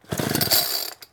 DayZ-Epoch/SQF/dayz_sfx/chainsaw/start-attempt.ogg at 804ca00d8c8cbebcf699e0f3d80774ce04fcce06